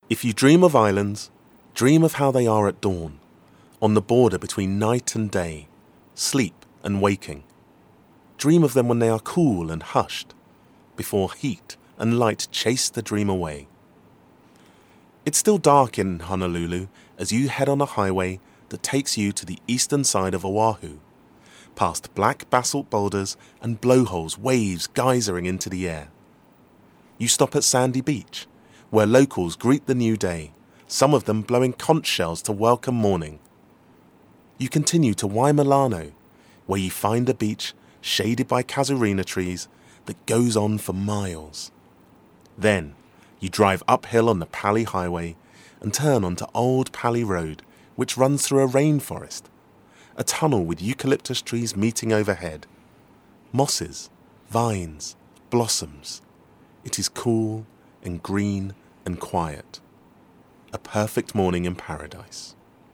• Native Accent: London, RP, African